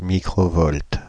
Ääntäminen
France (Île-de-France): IPA: /mi.kʁo.vɔlt/